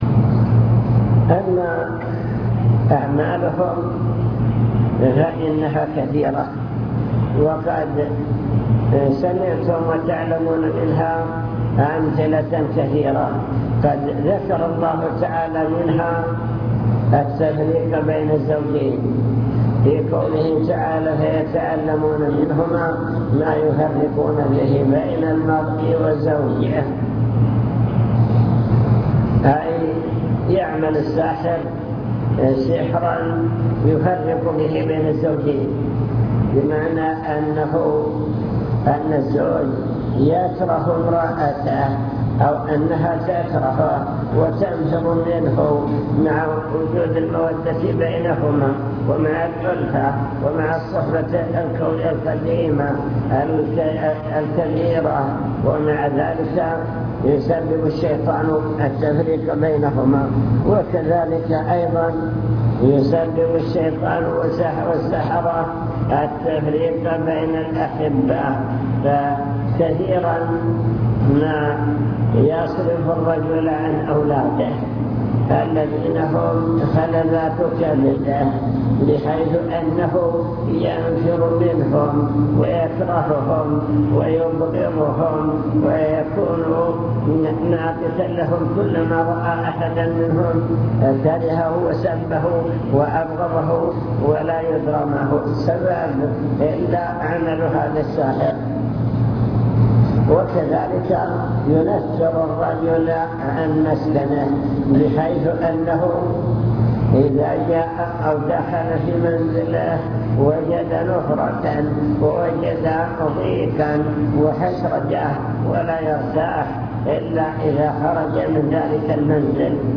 المكتبة الصوتية  تسجيلات - محاضرات ودروس  محاضرة بعنوان السحر وأثره في العقيدة